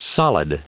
Prononciation du mot solid en anglais (fichier audio)
Prononciation du mot : solid